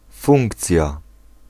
Ääntäminen
US : IPA : /ˈfʌŋkʃən/